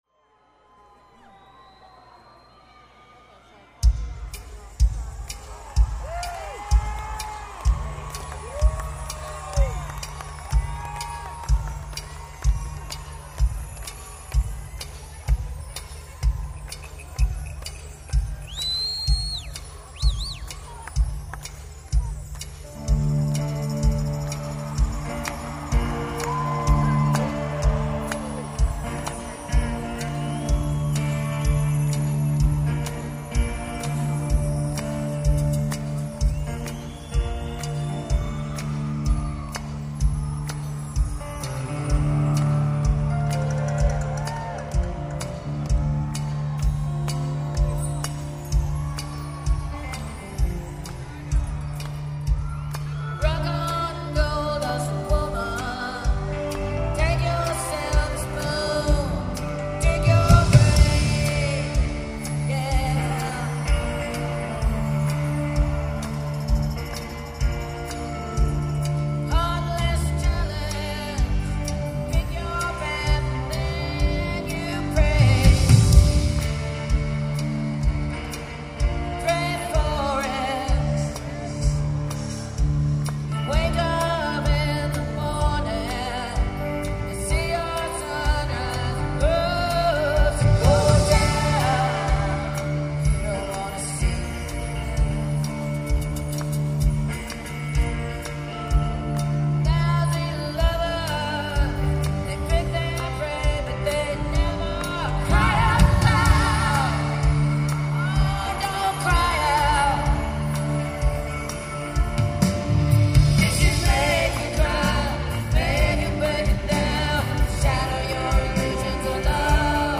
The Arena @ Gwinnett Center
Source: Audience DAT Master